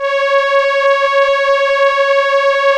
Index of /90_sSampleCDs/Keyboards of The 60's and 70's - CD1/STR_Elka Strings/STR_Elka Cellos